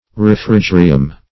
Search Result for " refrigerium" : The Collaborative International Dictionary of English v.0.48: Refrigerium \Ref`ri*ge"ri*um\ (r?f`r?*j?"r?*?m), n. [L.]